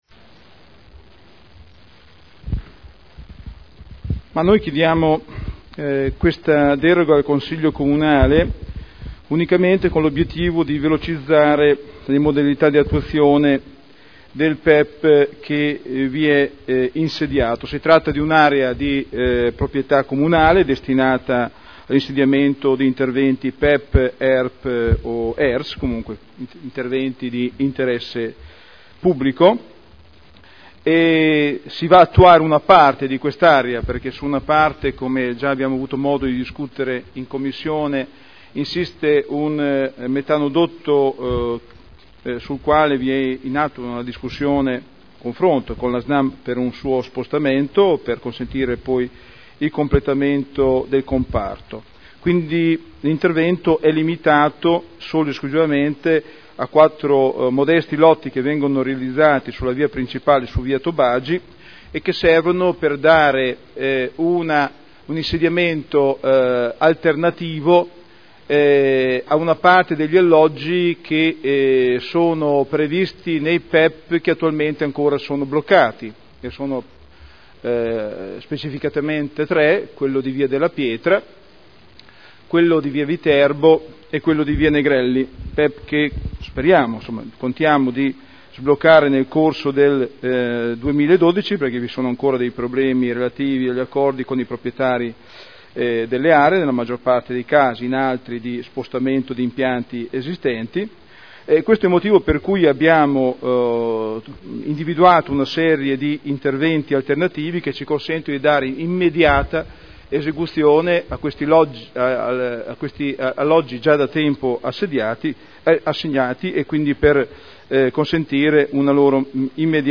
Seduta del 14 novembre Zona elementare n. 50 Area 13 di proprietà comunale - Parere favorevole all'attuazione delle previsioni urbanistiche dell'area con permesso di costruire convenzionato in deroga al Piano particolareggiato (Art. 31.23 RUE)